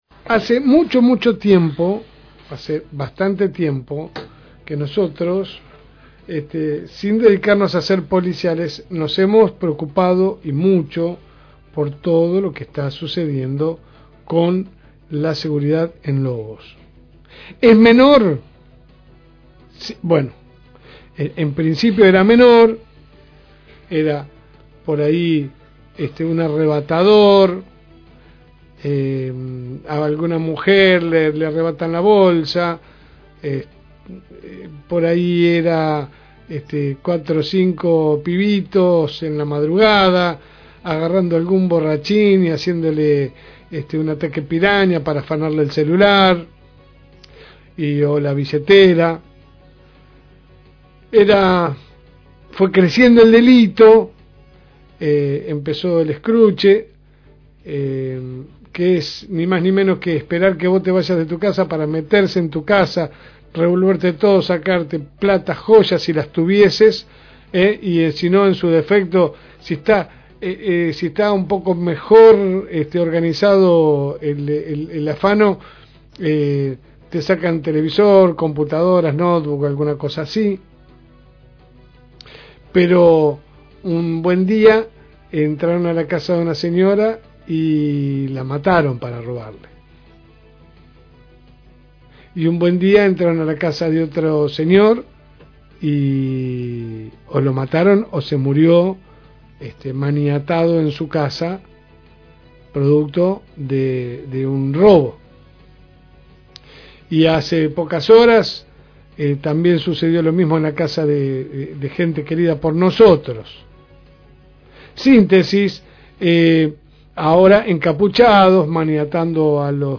EDITORIALES (8)